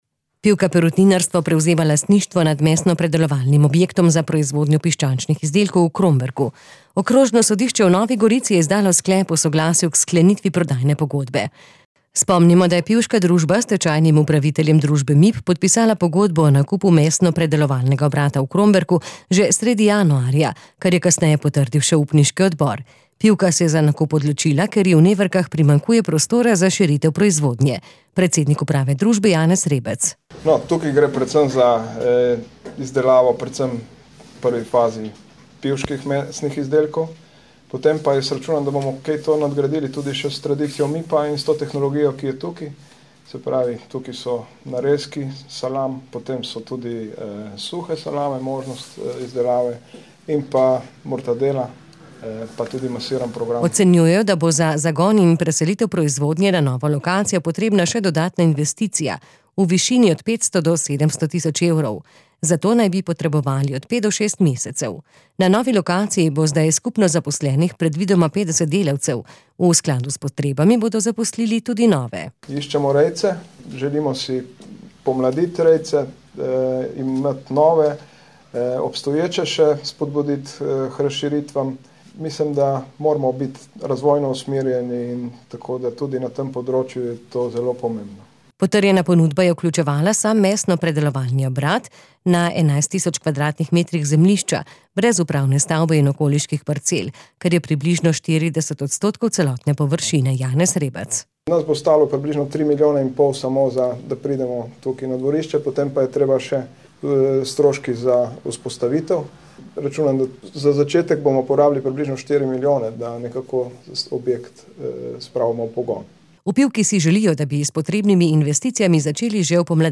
p318-88-pivka-tiskovka.mp3